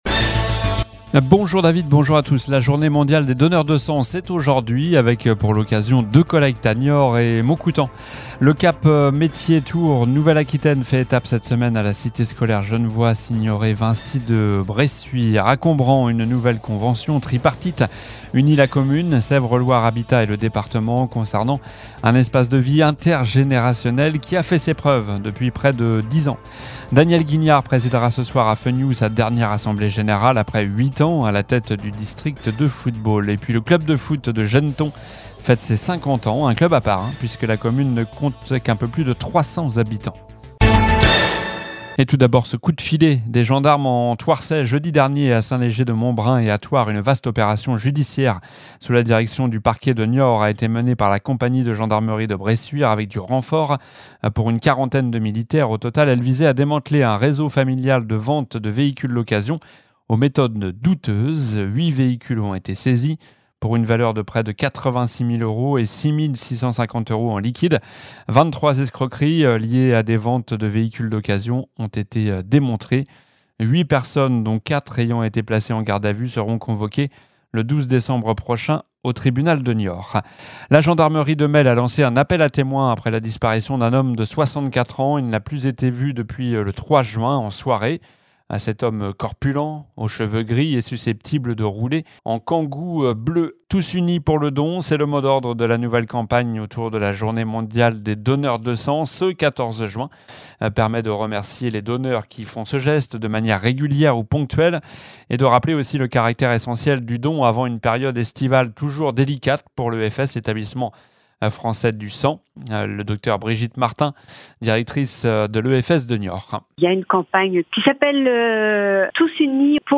Journal du vendredi 14 juin (midi)